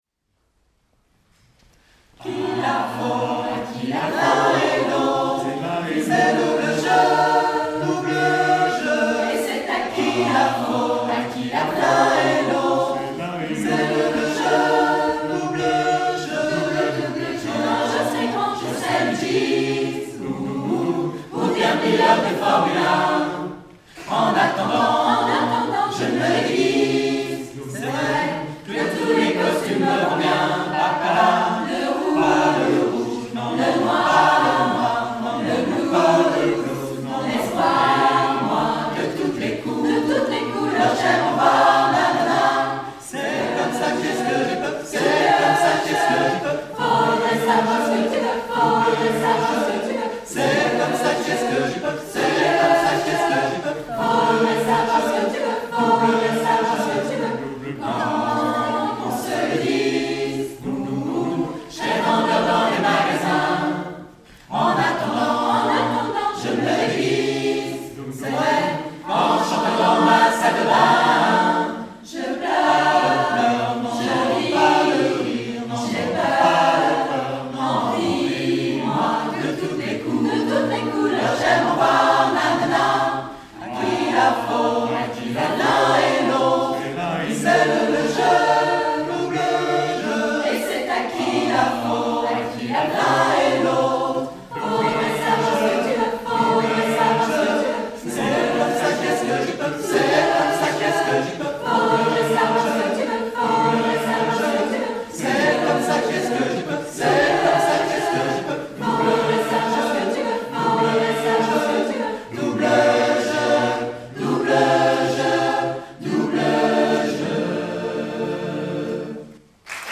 HISTORIQUE DES CHANTS HARMONISES & INTERPRETES DEPUIS 2005